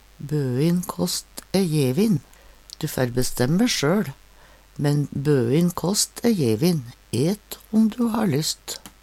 DIALEKTORD PÅ NORMERT NORSK Bøin kåst æ jevin. Buden kost er gratis.